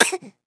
Baby Cough Sound
human
Baby Cough